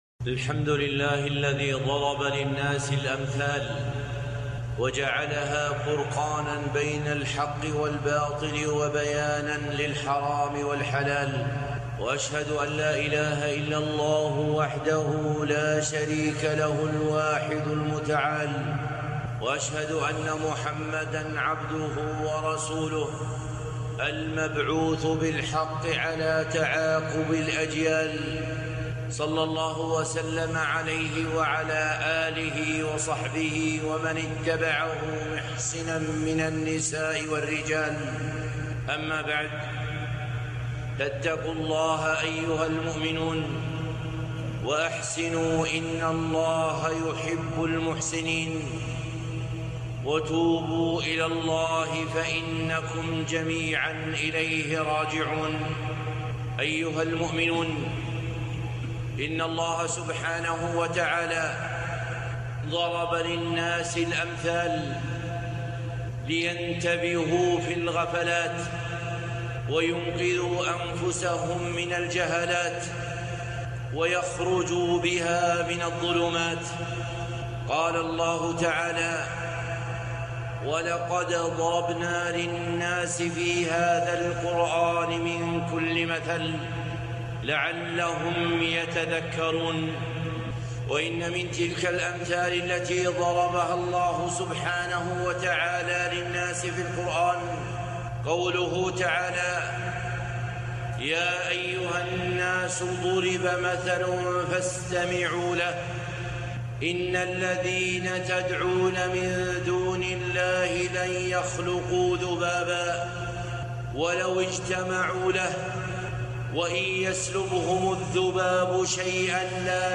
خطبة - الطرائق الناجحة في دفع الجائحة